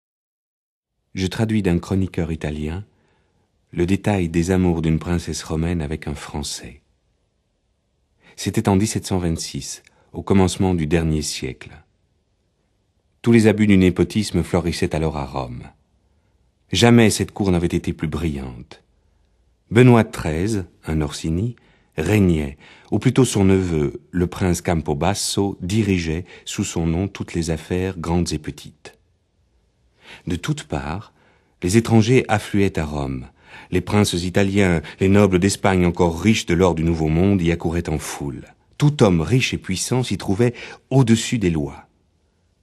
Click for an excerpt - San Francesco a Ripa de Henri Beyle dit Stendhal